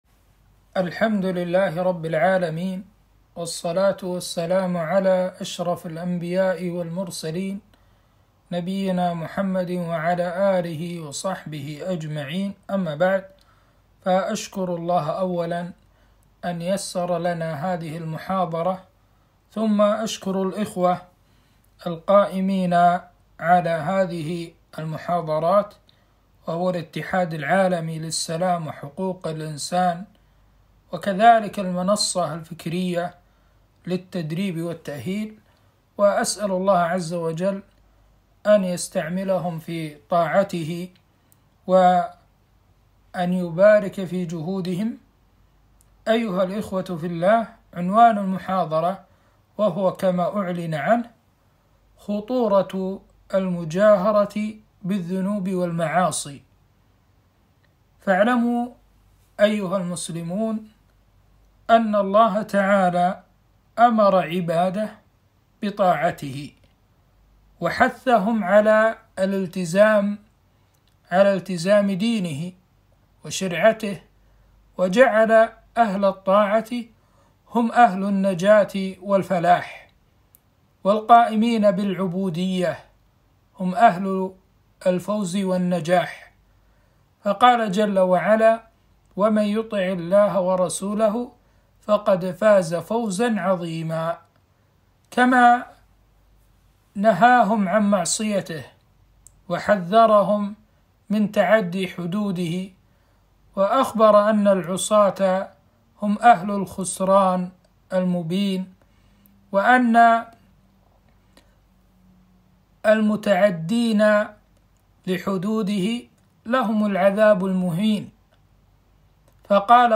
خطورة المجاهرة بالذنوب والمعاصي - في مركز الاتحاد العالمي للسلام وحقوق الإنسان